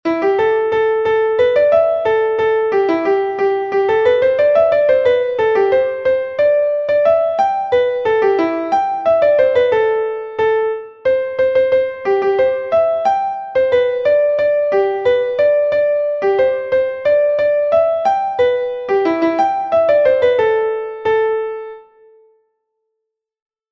This is a song which was written about Mendip but which was never sung on Mendip, in my opinion because it is sung to a Scottish tune which is difficult to sing comunally but is also unknown and alien to Mendip.